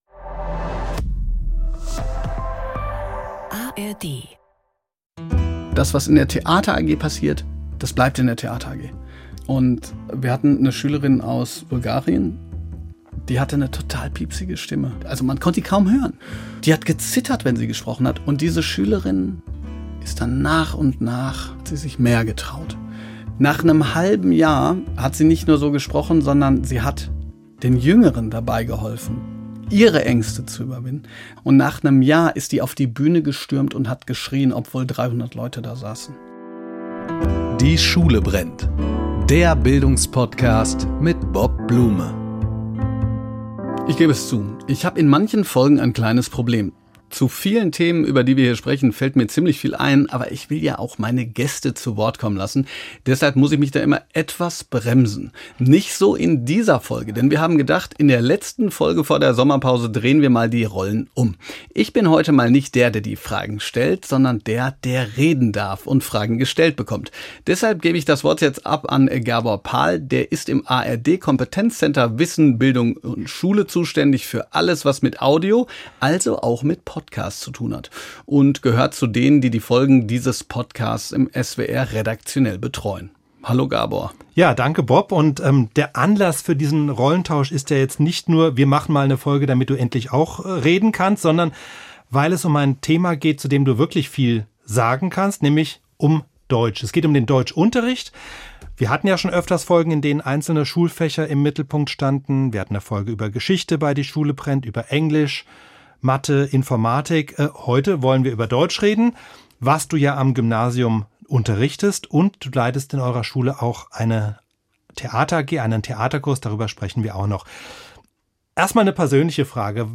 Deshalb darf er heute Antworten geben.